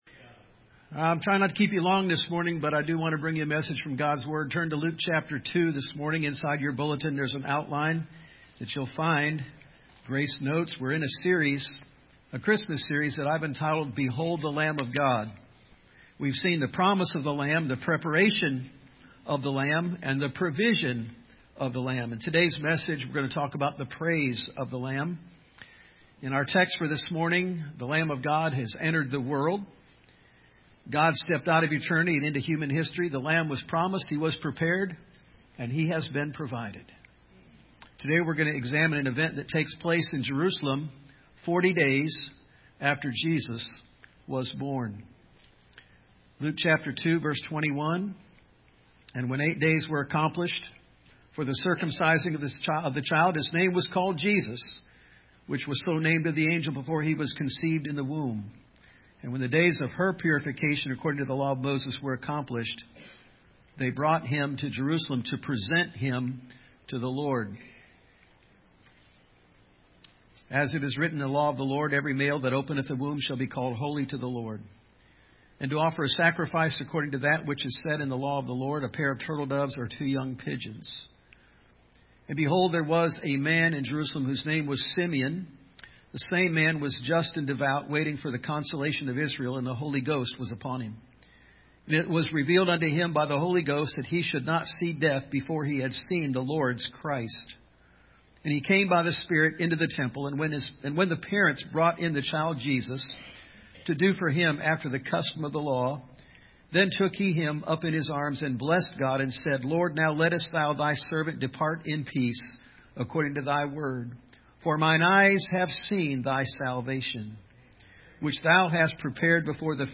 2020 The Praise of the Lamb Preacher